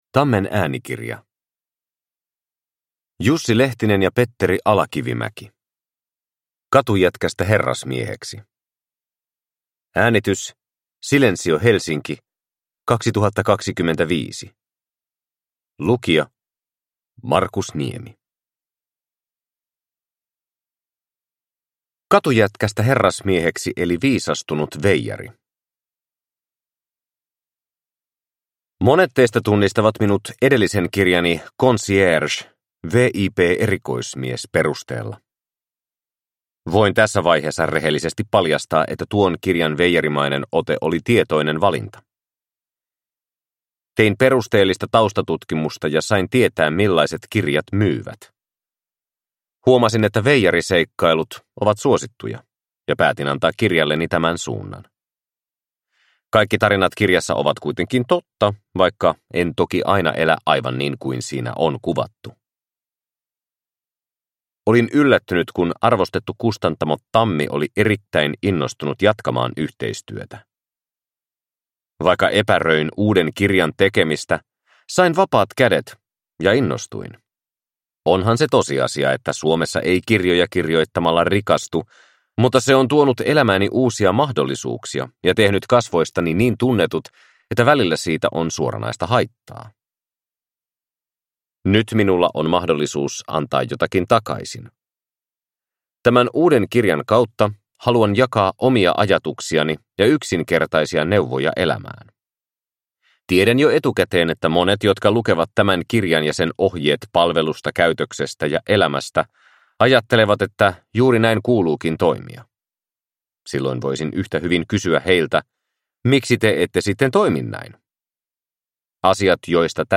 Katujätkästä herrasmieheksi (ljudbok) av Petteri Ala-Kivimä…